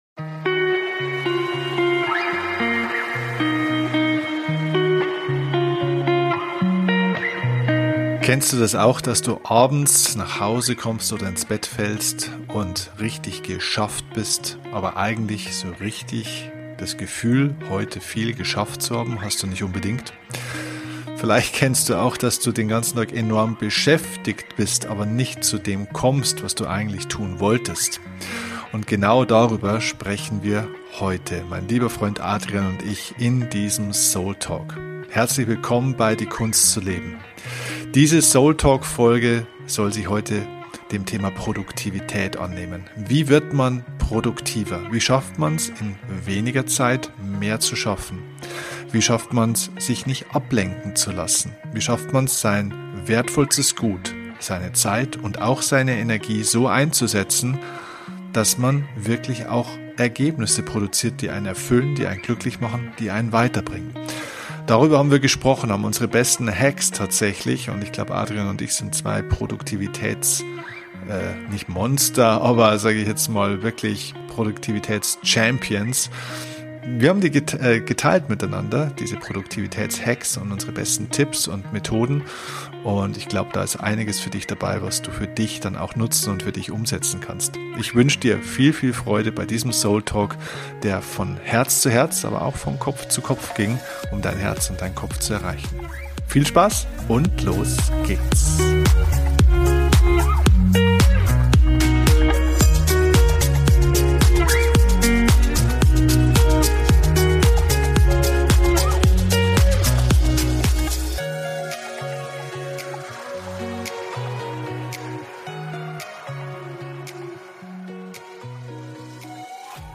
Wie immer bei diesem Format: kein Skript, nur zwei Freunde die miteinander reden.